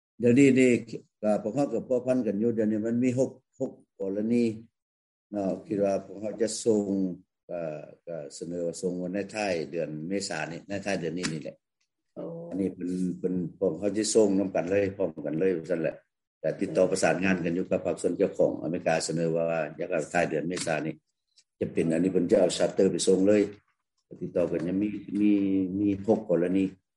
ສຽງ 1 ທ່ານທູດຄຳພັນ ອັ່ນລາວັນກ່າວກ່ຽວກັບການຮັບເອົາຄົນເນລະເທດກັບ